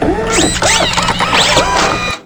repair.wav